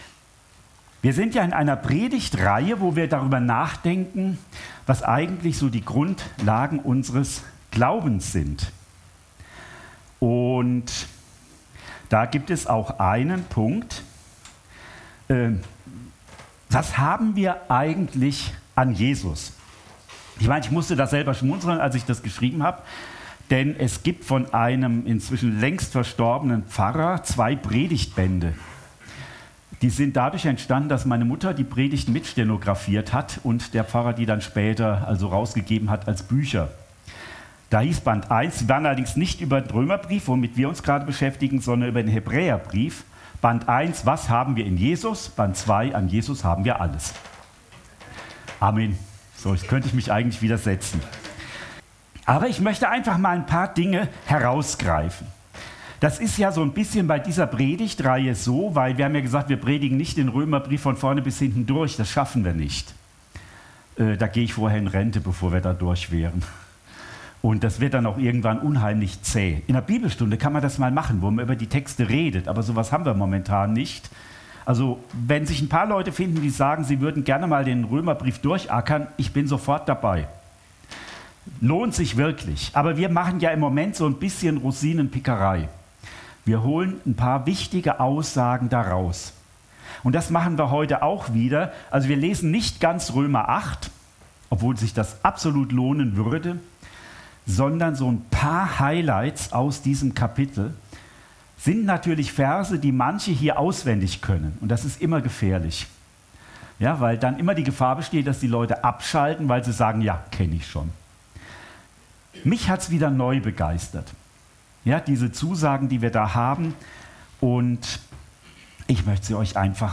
Predigt vom 16. Juni 2024 – Süddeutsche Gemeinschaft Künzelsau